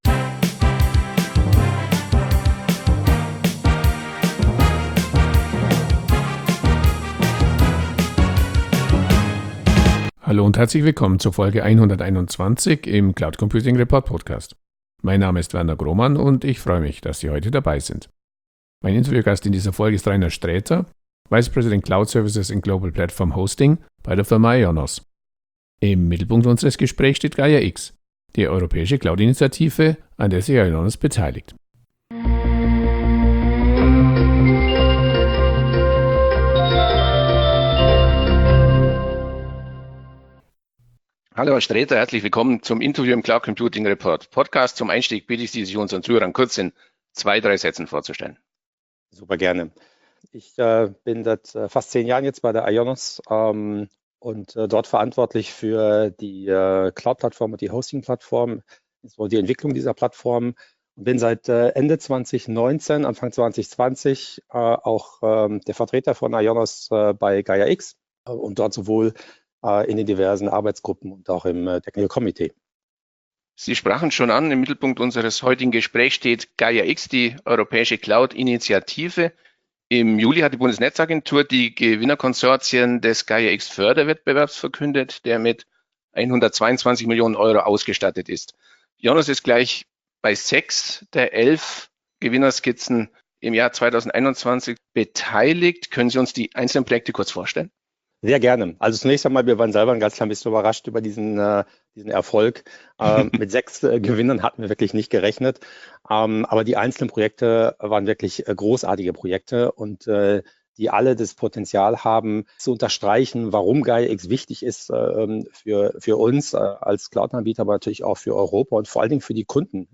Der Cloud Computing Report Podcast liefert aktuelle News und Hintergrundberichte zum deutschen Cloud Computing-Markt. Darüber hinaus kommen im Cloud Computing Report Podcast Kenner des deutschsprachigen Cloud Computing-Marktes zu Wort.